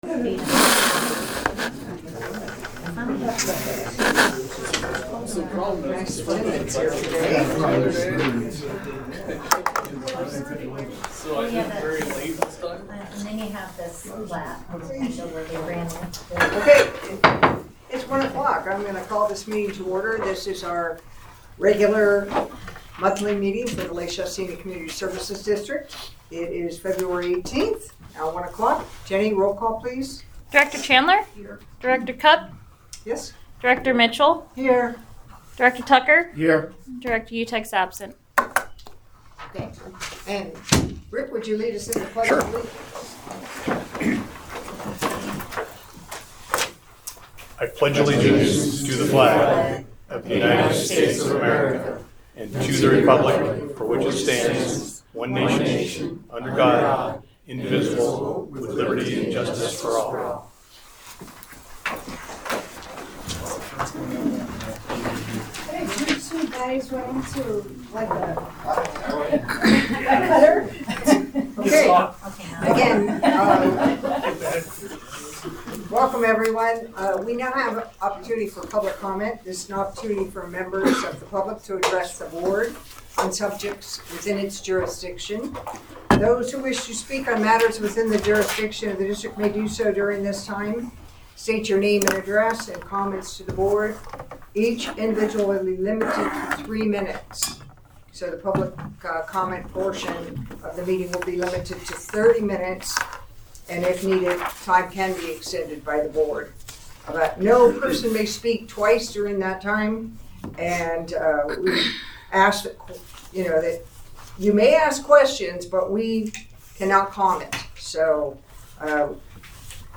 The LSCSD Board of Directors meets monthly on the third Wednesday at 1:00 p.m. at the Administration Building.
Board Meeting